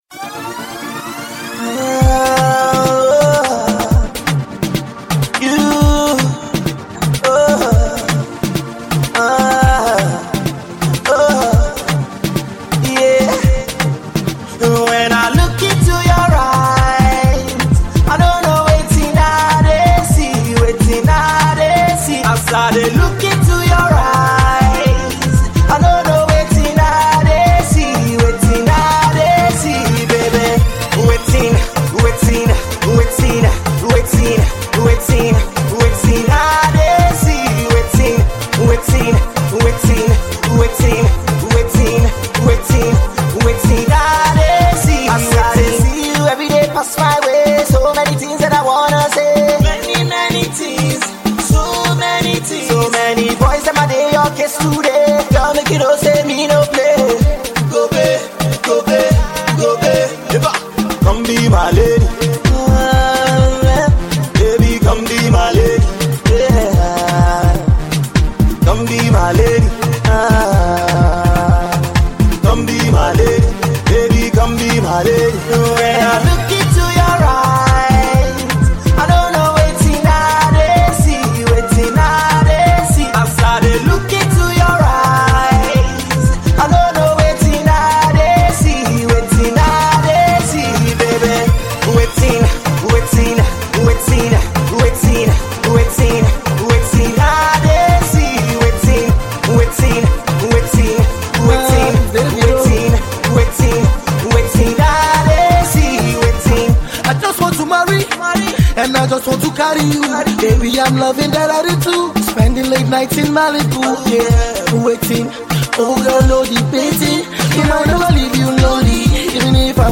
mid-tempo vibe